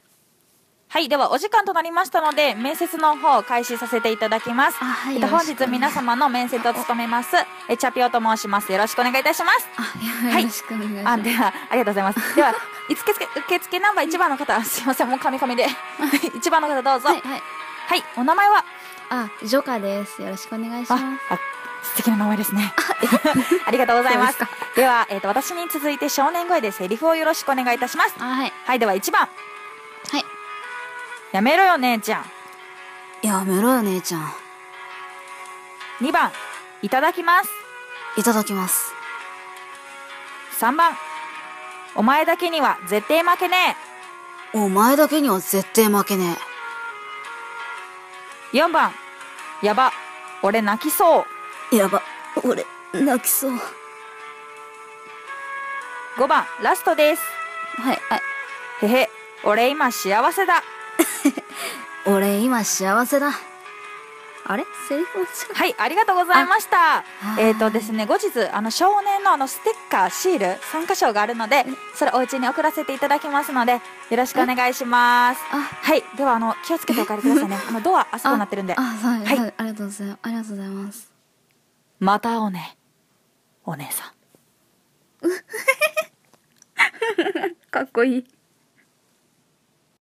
少年声面接